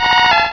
sound / direct_sound_samples / cries / phanpy.aif
phanpy.aif